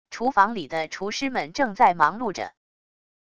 厨房里的厨师们正在忙碌着wav音频